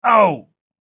دانلود صدای درد آمدن از ساعد نیوز با لینک مستقیم و کیفیت بالا
جلوه های صوتی